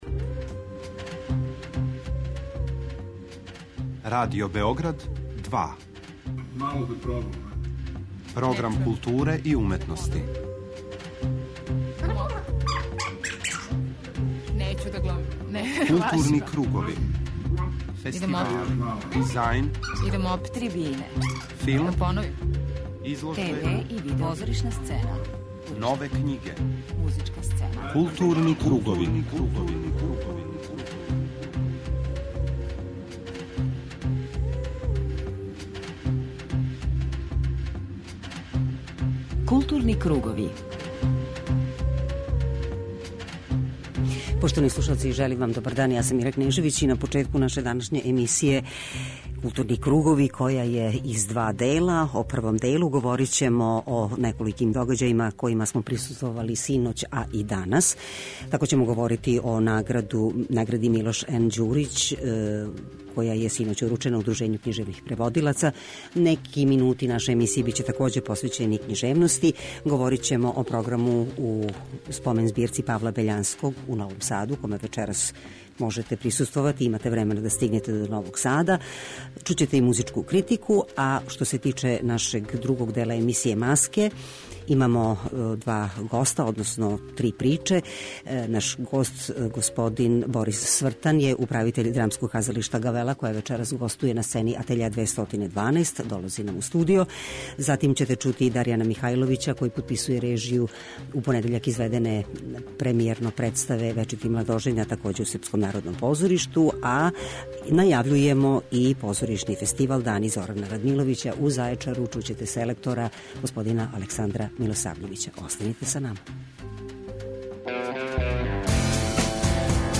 Гост Маски је Борис Свртан, управитељ Градског драмског казалишта Гавела, из Загреба, које гостује данас у Атељеу 212 са представом "Као на небу", Кеја Полака, у режији Ренеа Медвешека.